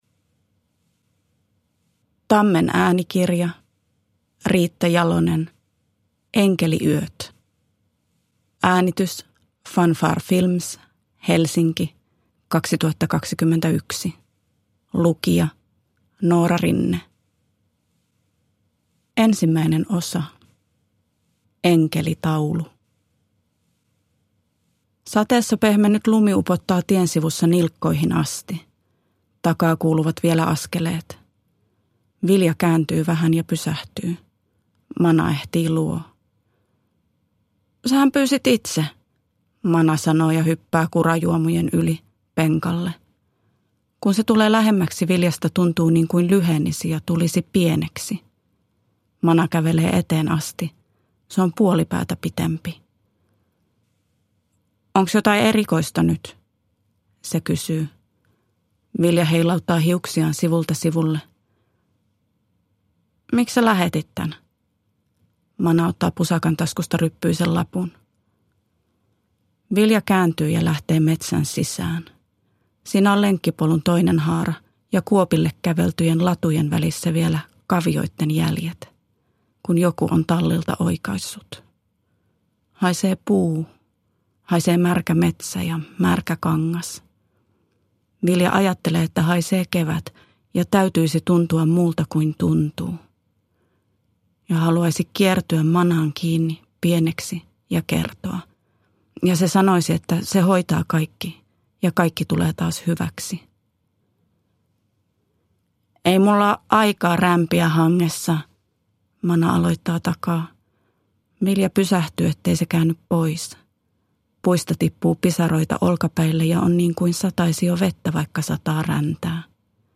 Enkeliyöt – Ljudbok – Laddas ner